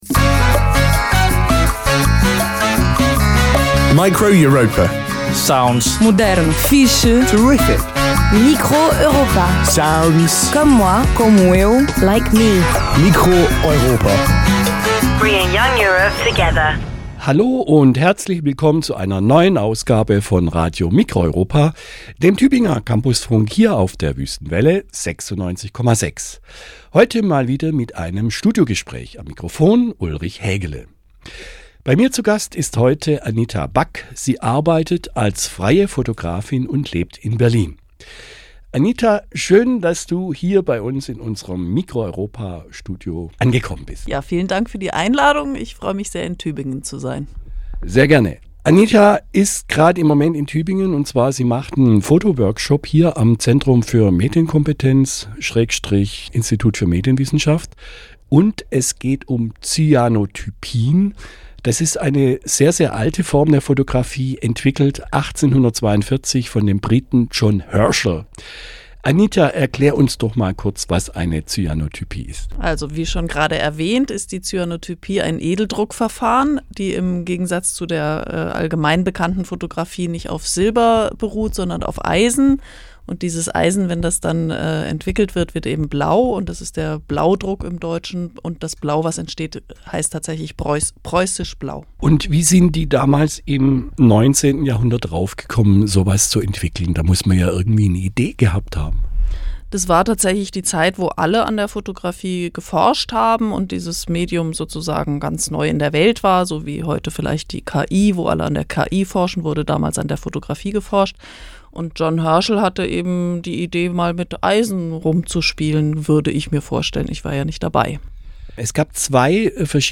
Studiogespräch
Form: Live-Aufzeichnung, geschnitten